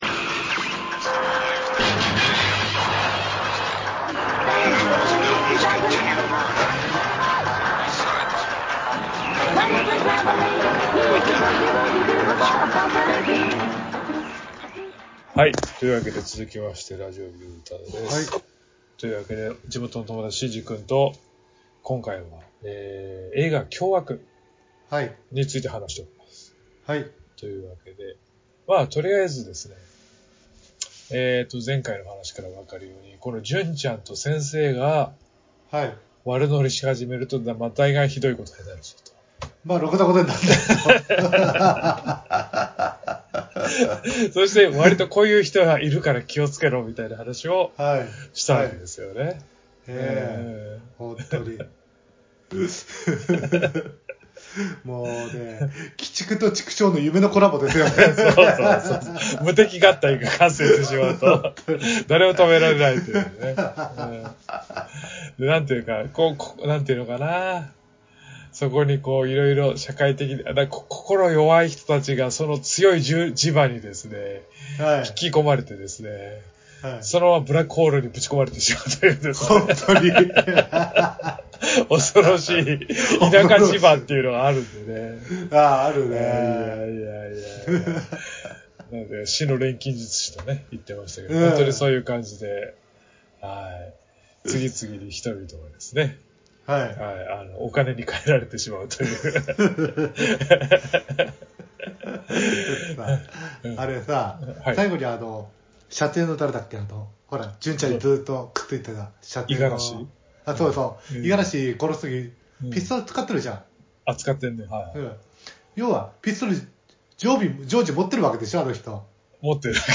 アメコミやバンドデシネがちょっと好きで、ちょっとアレな二人の男子が、至高の女子会を目指すエンタテインメントネットラジオです。